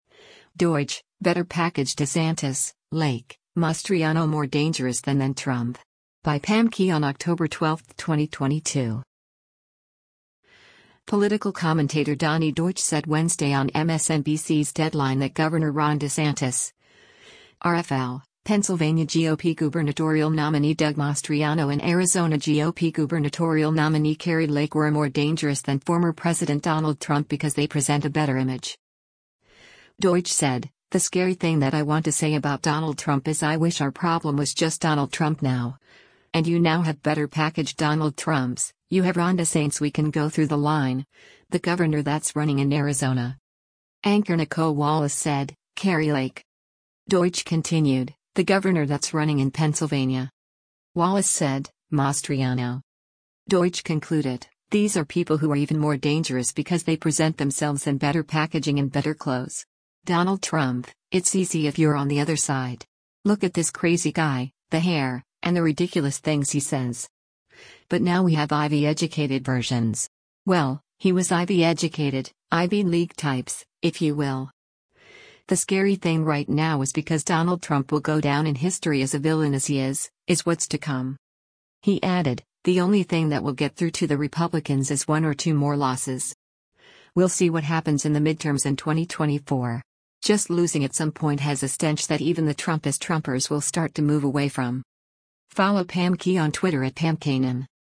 Political commentator Donny Deutsch said Wednesday on MSNBC’s “Deadline” that Gov. Ron DeSantis (R-FL), Pennsylvania GOP gubernatorial nominee Doug Mastriano and Arizona GOP gubernatorial nominee Kari Lake were “more dangerous” than former President Donald Trump because they present a better image.